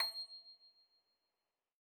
53l-pno26-C6.wav